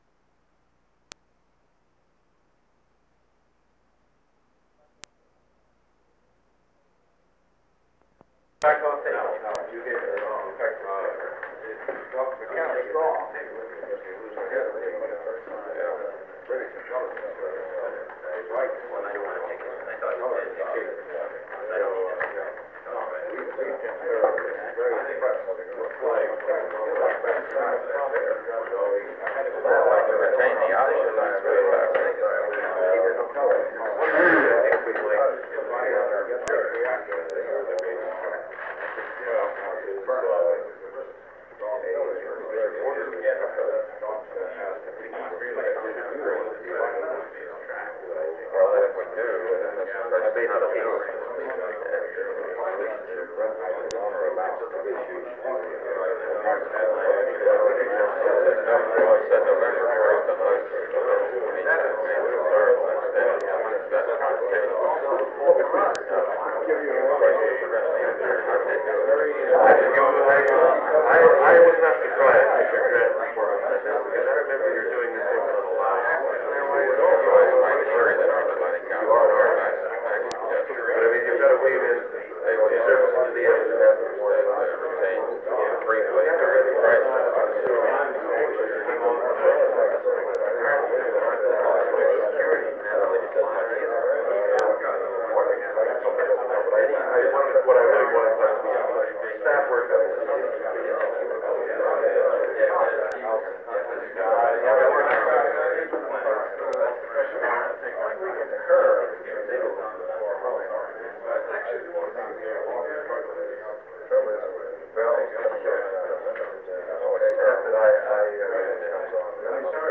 Secret White House Tapes | John F. Kennedy Presidency Meeting on the Cuban Missile Crisis Rewind 10 seconds Play/Pause Fast-forward 10 seconds 0:00 Download audio Previous Meetings: Tape 121/A57.